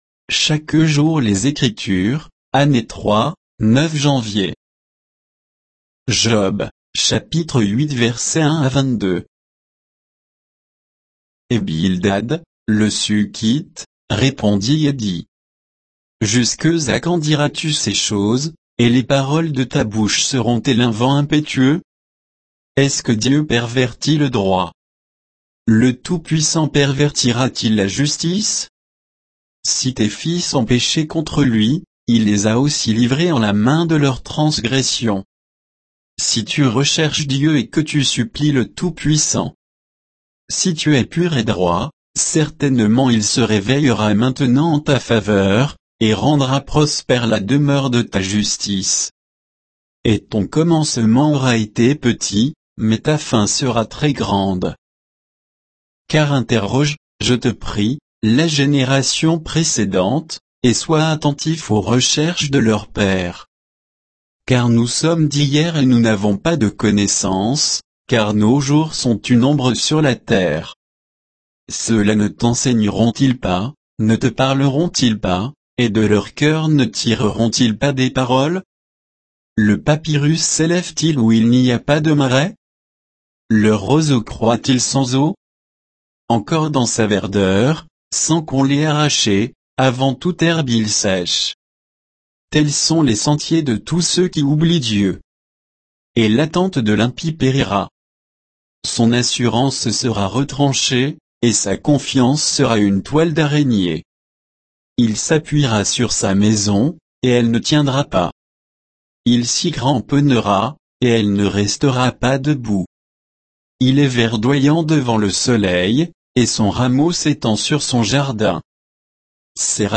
Méditation quoditienne de Chaque jour les Écritures sur Job 8